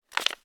paper_putdown1.wav